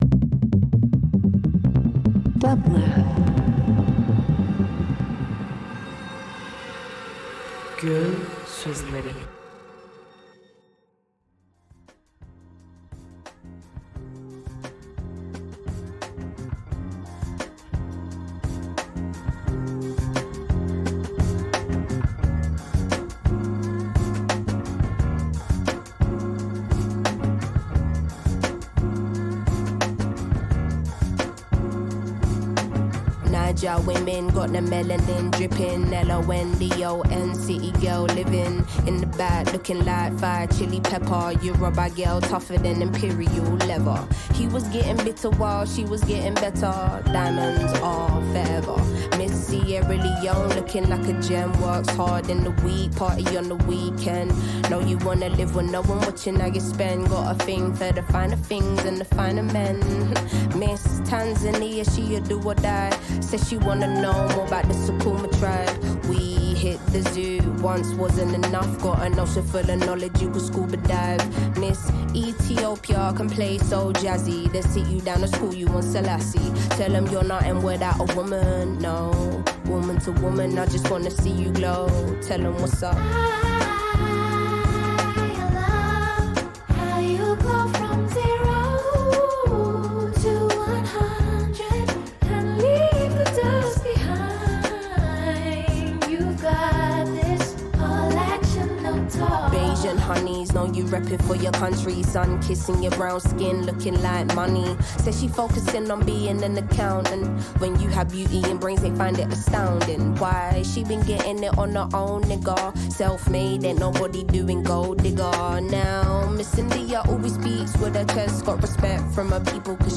Each episode is a peek into the practice of storytellers through different mediums. Curating advice and visibility for communities navigating the emerging landscape of art, technology, and entrepreneurship. Between conversations, featured guests share a playlist of their most played recent tunes, as a sonic backdrop to their creative process.
The show is recorded in the NEW INC office, reimagined as a vessel for dissecting the intersectionality of art, design, and technology through individual conversations.